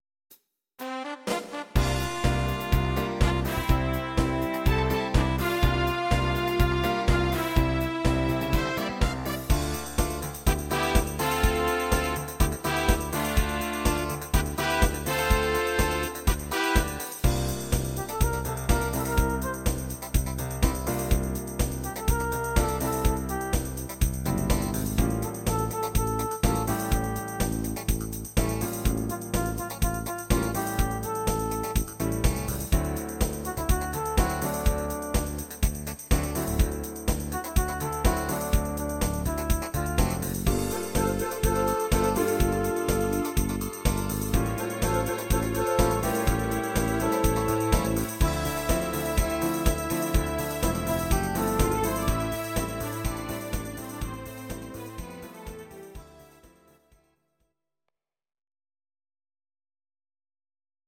Audio Recordings based on Midi-files
Pop, Disco, 1970s